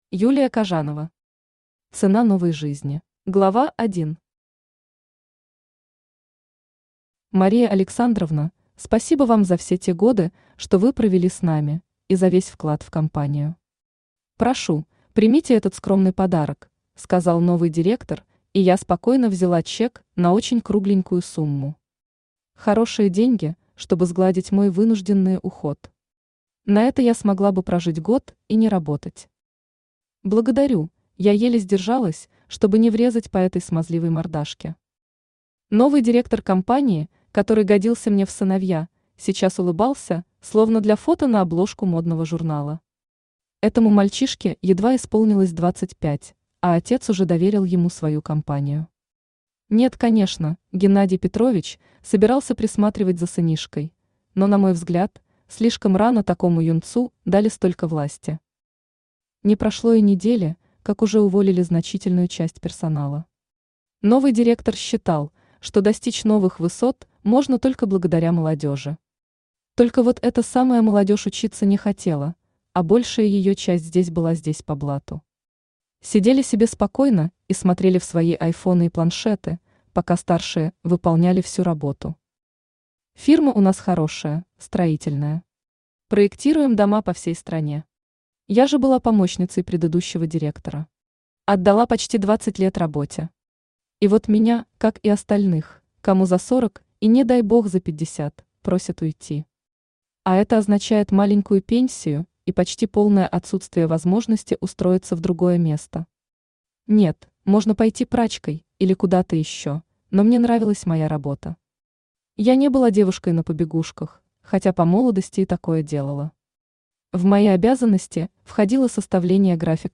Aудиокнига Цена новой жизни Автор Юлия Витальевна Кажанова Читает аудиокнигу Авточтец ЛитРес.